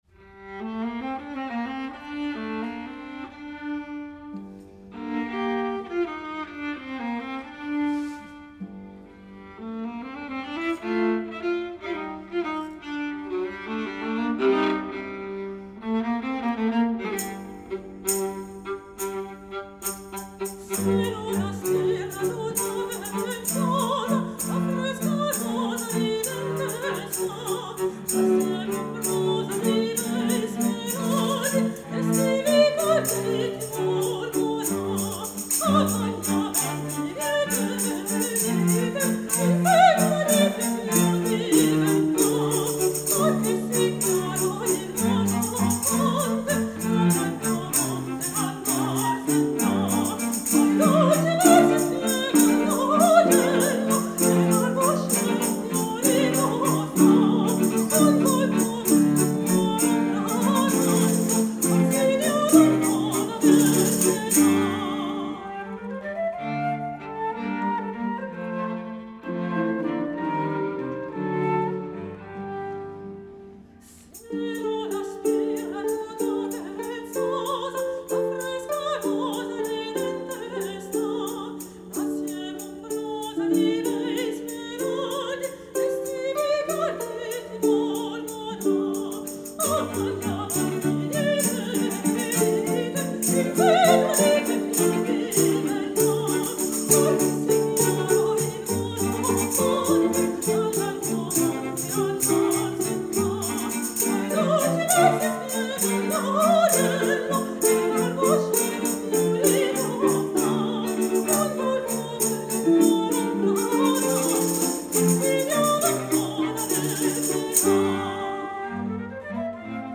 Arrangement d'un chant